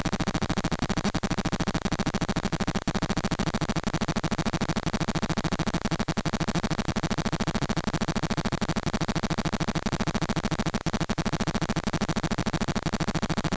zigbee data transfer, updating device firmware.
Zigbee_xfer.wav